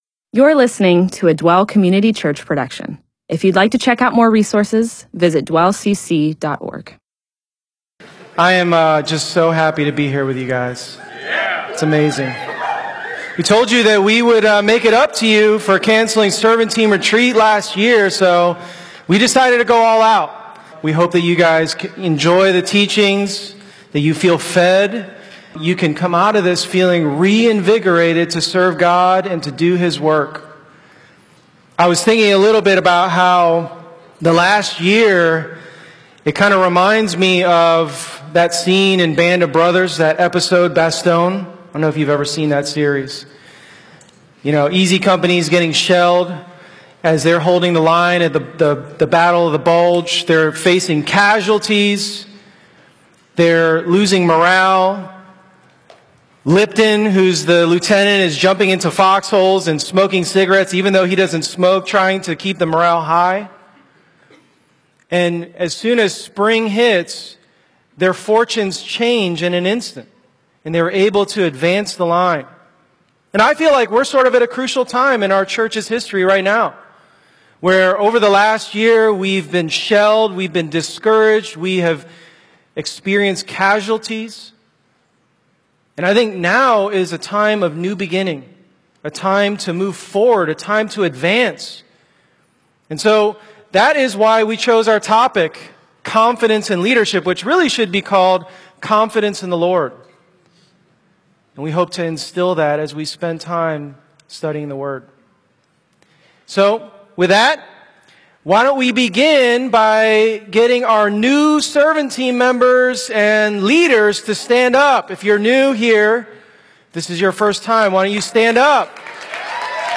MP4/M4A audio recording of a Bible teaching/sermon/presentation about Joshua 3-4.